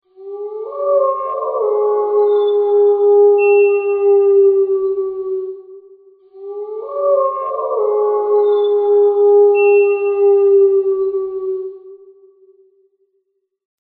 wolf-ringtone_14182.mp3